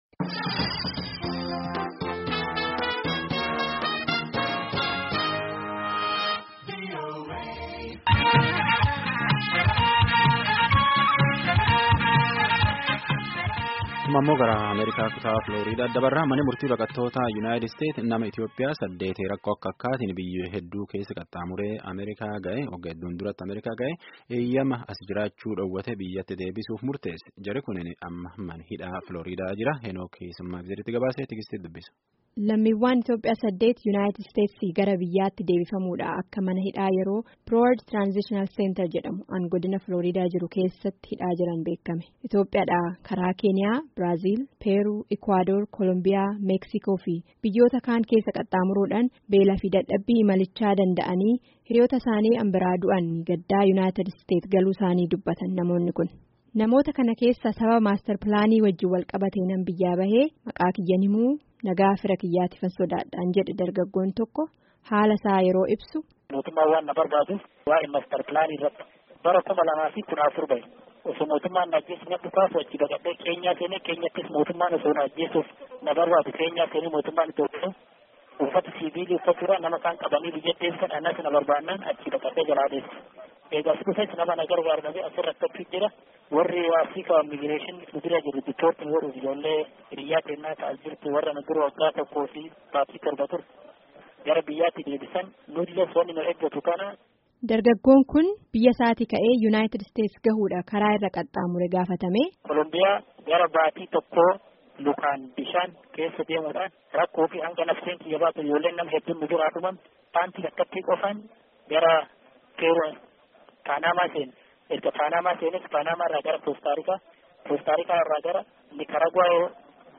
Gabaasni sagalee kunooti